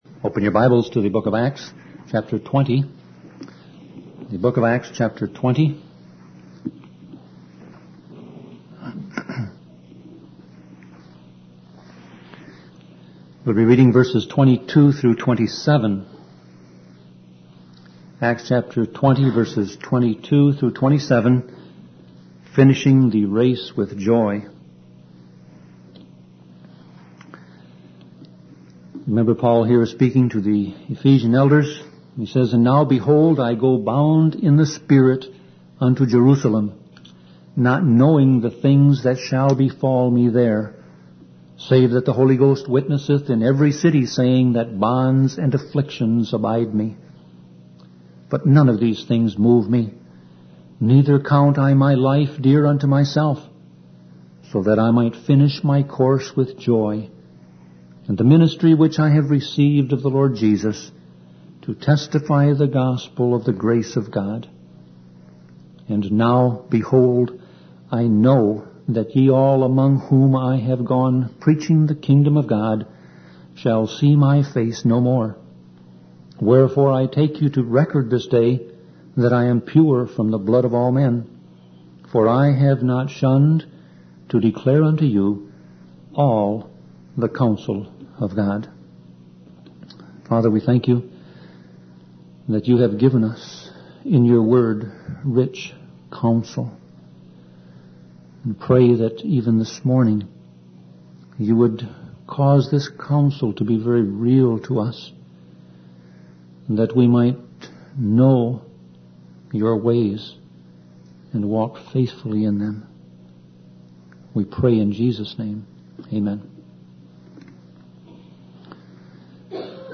Sermon Audio Passage: Acts 20:22-27 Service Type